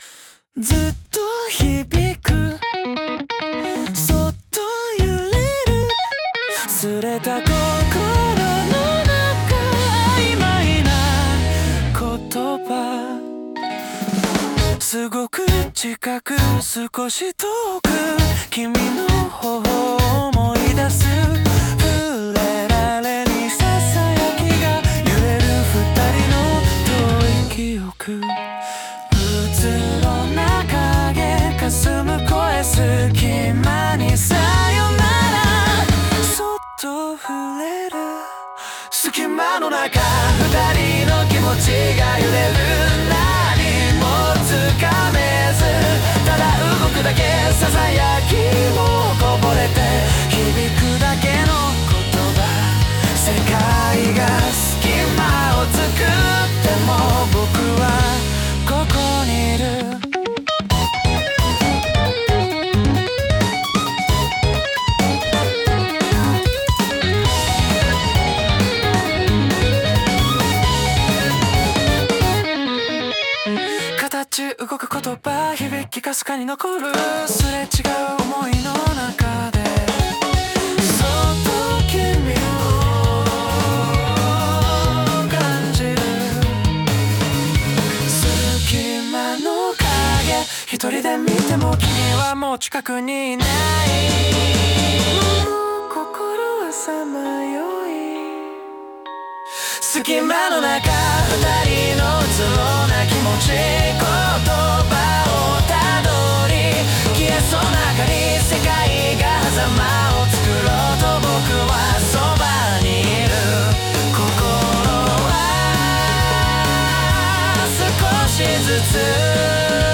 男性ボーカル
イメージ：シティーPOP,男性ボーカル,かっこいい,切ない